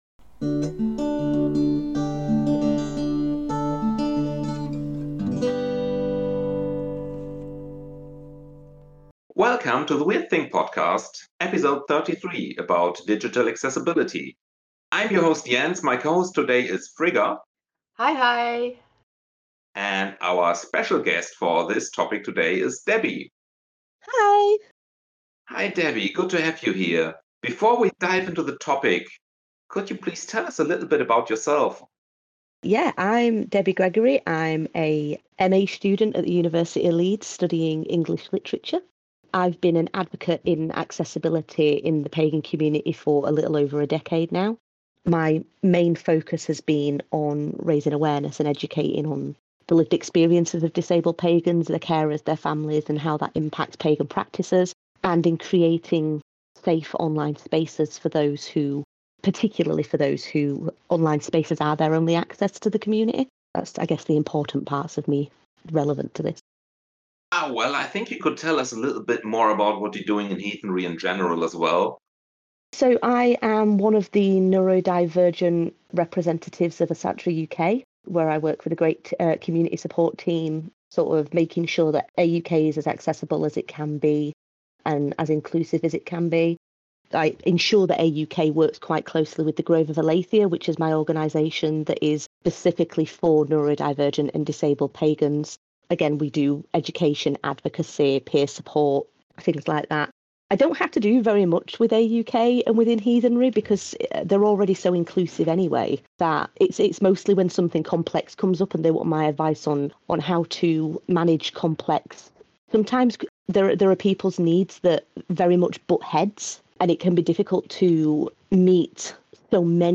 The Wyrd Thing is a heathen podcast on diversity, made by a diverse group of European heathens.